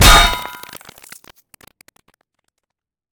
metal1.ogg